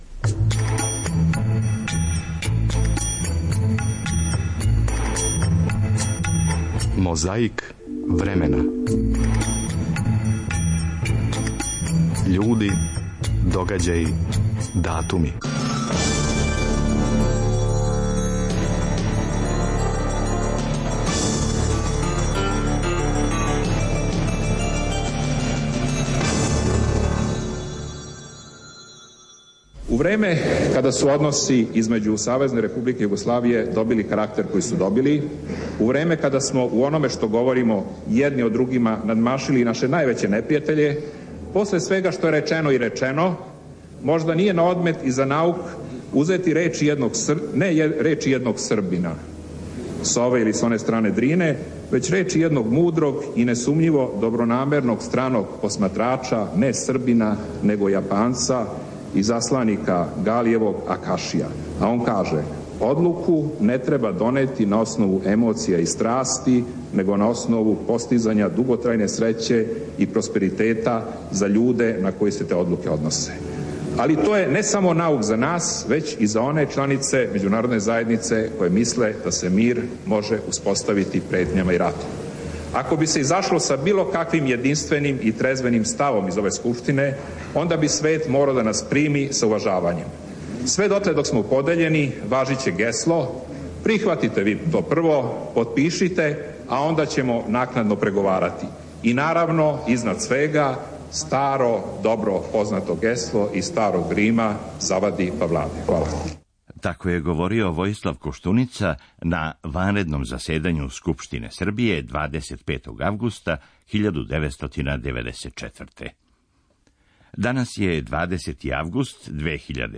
Слушамо делове излагања.